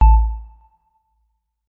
Melodic Power On 4.wav